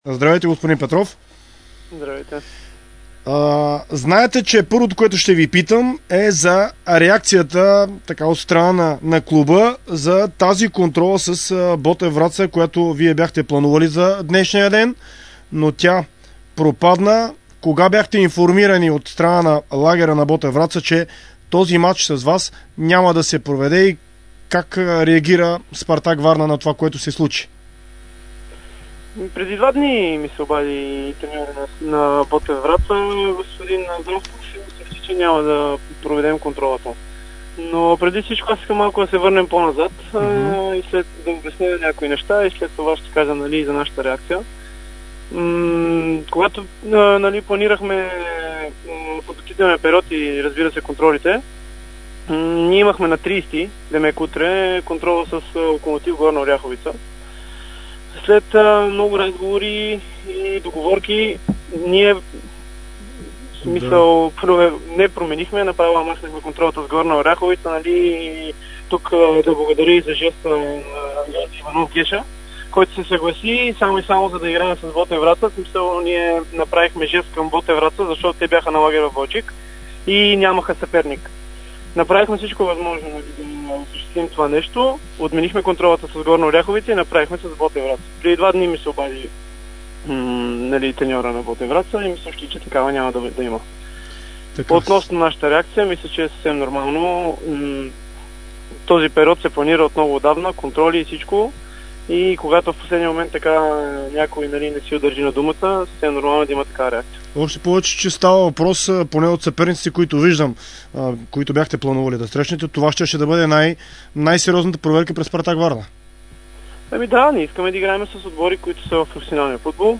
интервю за Дарик радио и dsport